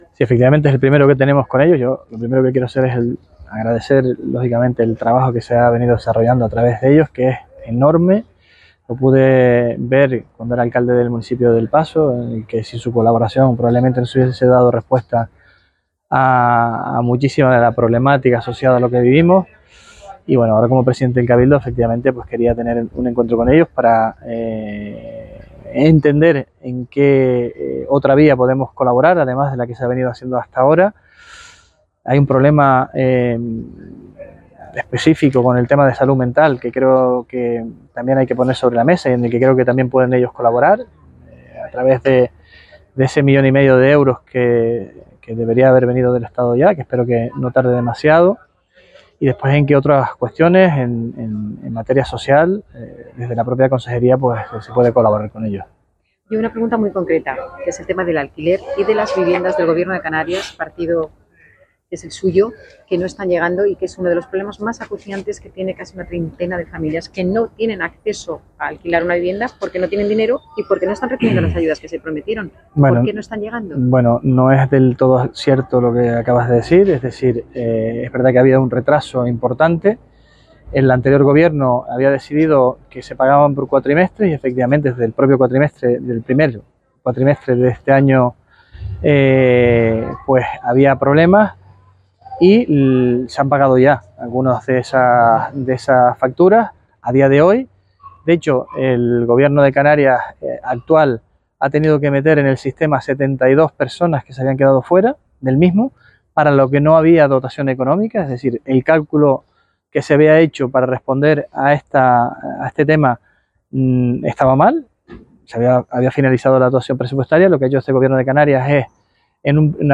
Declaraciones del presidente reunión Cáritas.mp3